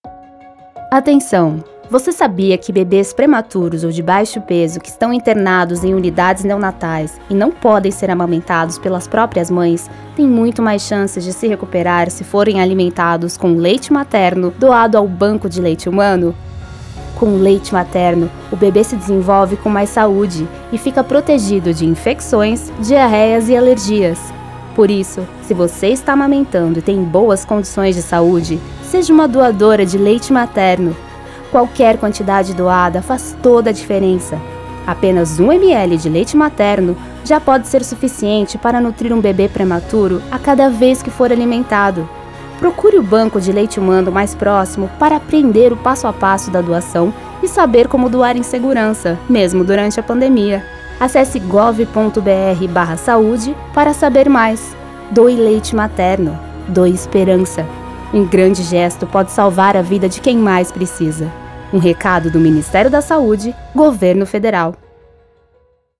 Carro de Som - Campanha da Doação de Leite Materno.mp3 — Ministério da Saúde
carro-de-som.mp3